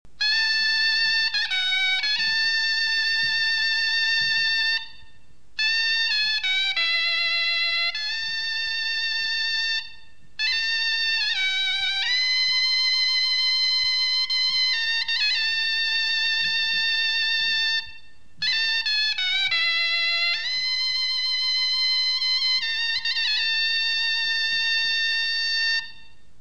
Instruments traditionnels arméniens
Zourna
Le zourna, sorte de hautbois primitif, a 8 trous plus 1 en dessous, rappelant la bombarde, et est doté d'une anche double.
le timbre perçant et criard du zourna, et sa puissance, empêchent son utilisation dans les ensembles traditionnels ; il couvrirait en effet la voix de tous les autres instruments.
zourna.mp3